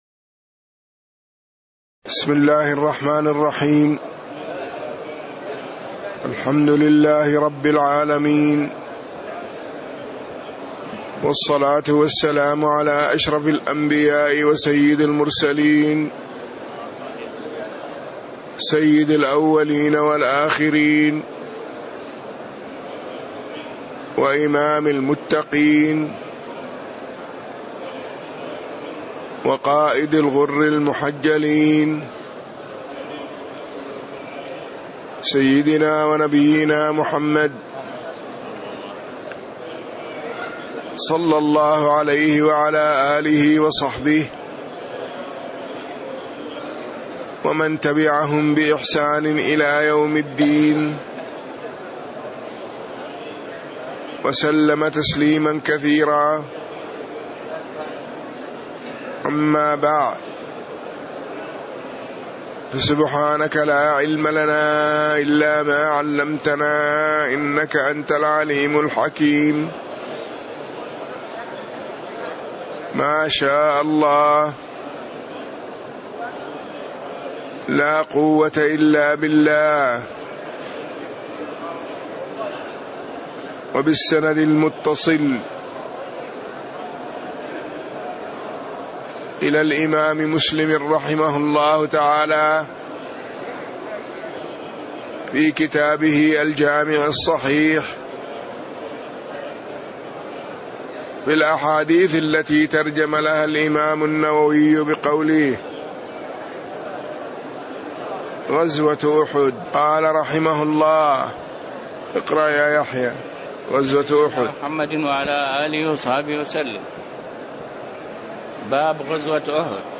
تاريخ النشر ٢٥ ذو الحجة ١٤٣٥ هـ المكان: المسجد النبوي الشيخ